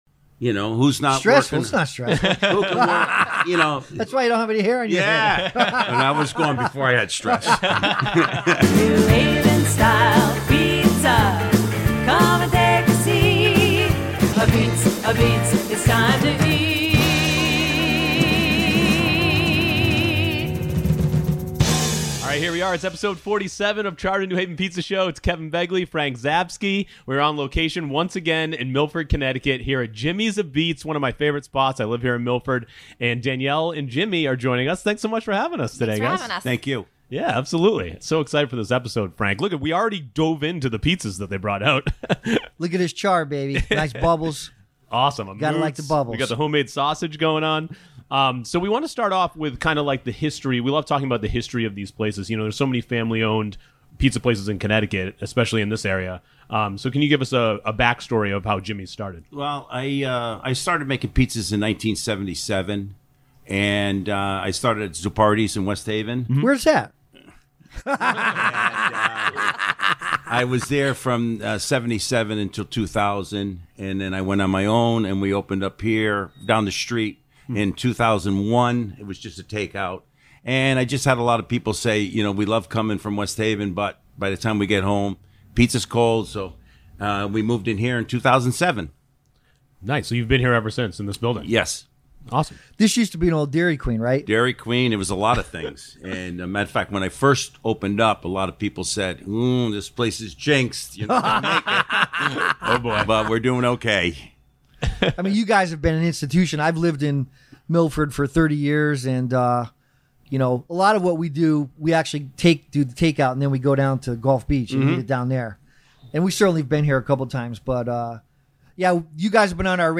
Charred is a New Haven Style Pizza centric talk show that runs two shows a month.
Monthly episodes feature different pizza industry guests from the New Haven and Connecticut pizza scene. Plus discussions, debate, and news about all things pizza.